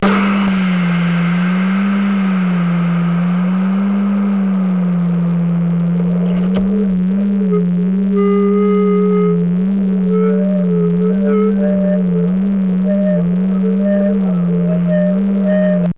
Plus tard, les données étaient lues au sol et on avait immédiatement un aperçu des résultats en connectant un haut parleur en sortie de notre enregistreur (les données étant en fait restituées en fréquences sonores).
L'enregistrement du son était lui aussi réalisé à l'aide d'un ISD, dont l'entrée était reliée à un microphone à électrets.
Son et rotation